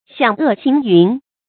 响遏行云 xiǎng è xíng yún
响遏行云发音
成语正音 遏，不能读作“yè”；行，不能读作“hánɡ”。